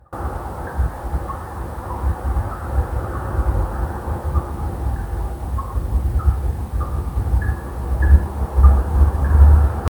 Il mistero del gocciolio infinito un piccolo giallo di Natale a Como su cui stanno indagando i residenti di via Rosales che da più di una settimana ascoltano questo suono costante e apparentemente senza senza spiegazione, ecco l’audio (abbiamo alzato il più possibile il volue) e poi la storia:
Pare una goccia d’acqua che batte su una pozza ma non si riesce a comprenderne la provenienza.
Goccia.mp3